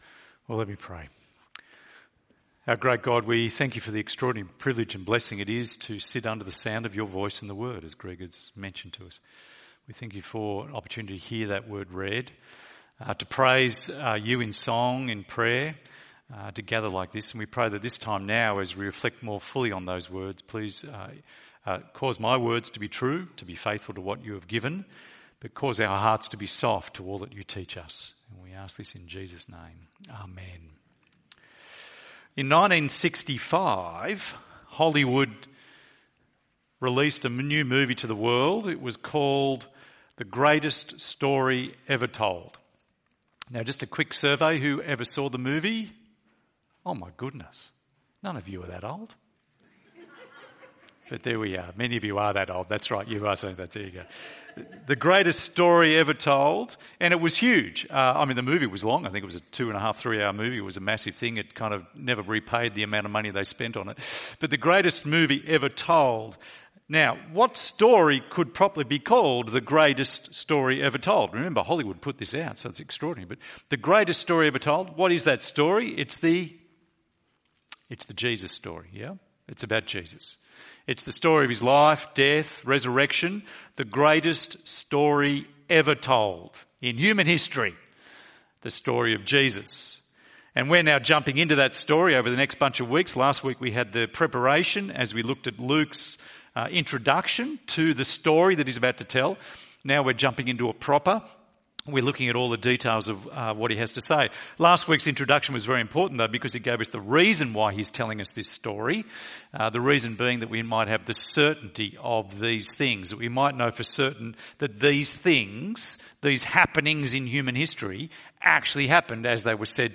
Kingdom Reversal ~ EV Church Sermons Podcast